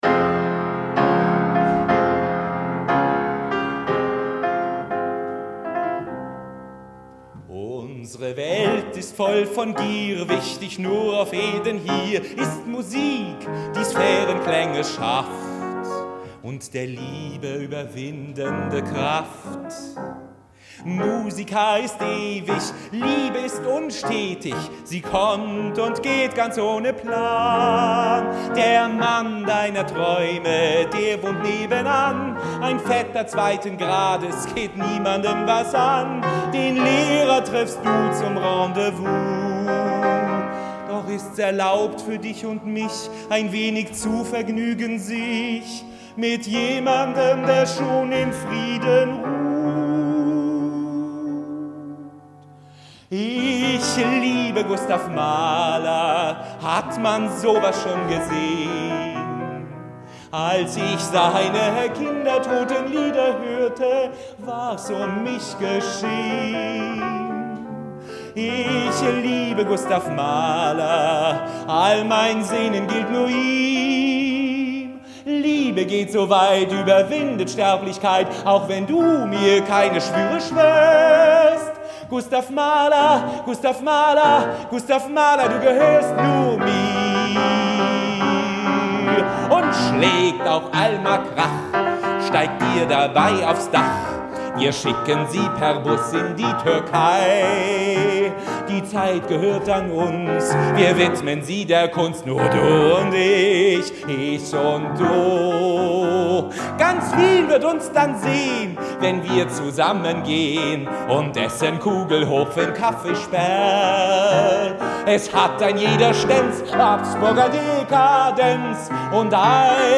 „Ringstraßenromanze“ – ein Chanson
am Klavier begleitet.